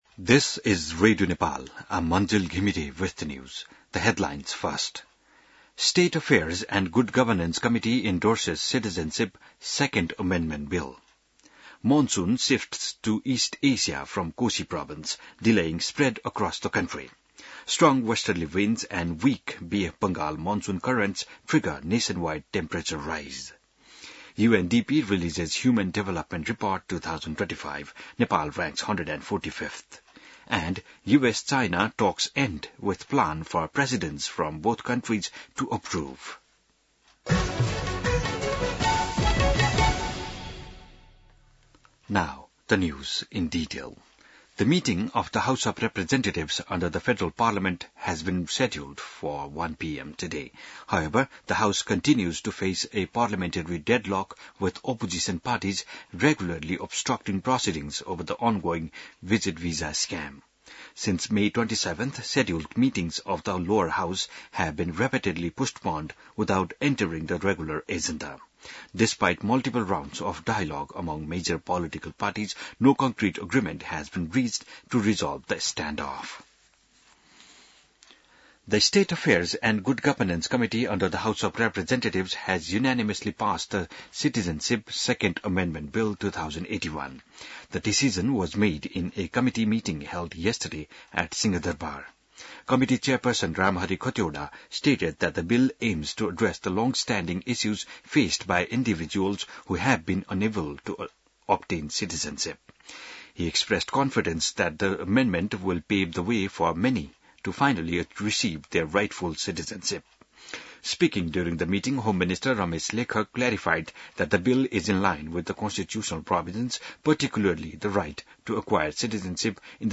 An online outlet of Nepal's national radio broadcaster
बिहान ८ बजेको अङ्ग्रेजी समाचार : २८ जेठ , २०८२